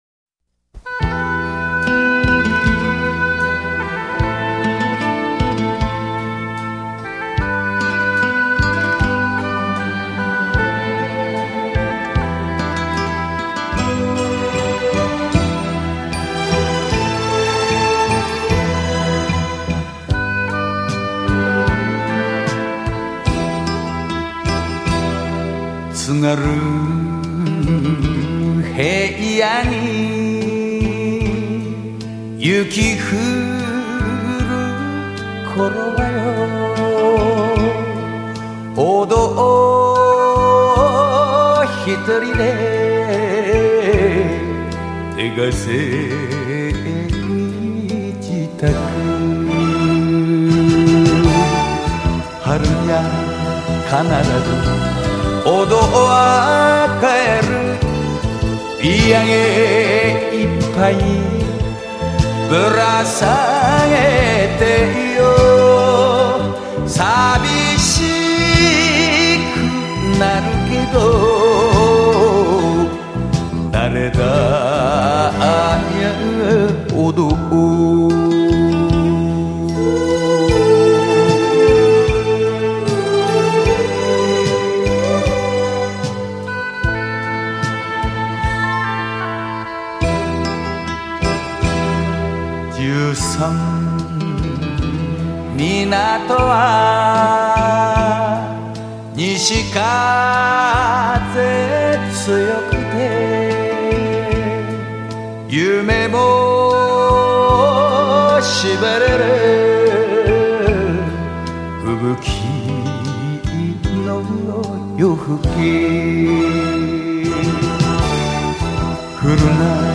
日本演歌精选
收录日本演歌精选/曲曲动听 朗朗上口